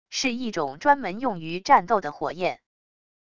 是一种专门用于战斗的火焰wav音频